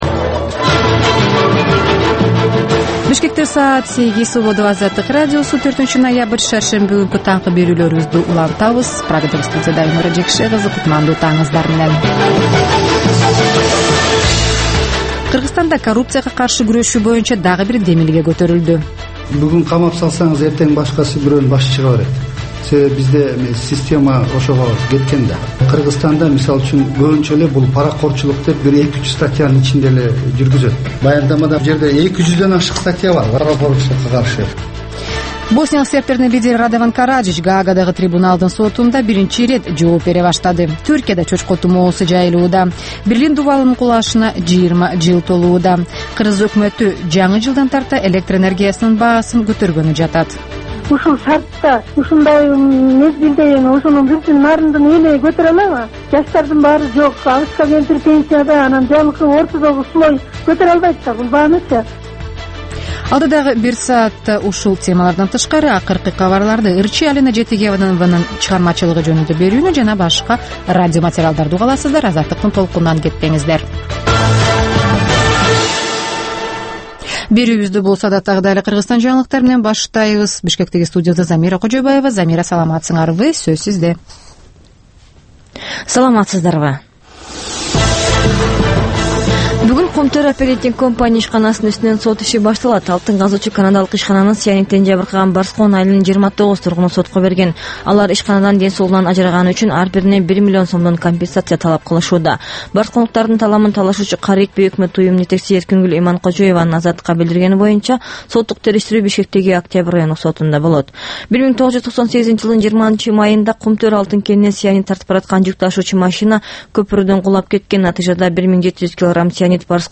Бул таңкы үналгы берүү жергиликтүү жана эл аралык кабарлардан, ар кыл окуялар тууралуу репортаж, маек, тегерек үстөл баарлашуусу, талкуу, баян жана башка берүүлөрдөн турат. "Азаттык үналгысынын" бул таңкы берүүсү Бишкек убактысы боюнча саат 08:00ден 09:00га чейин обого чыгарылат.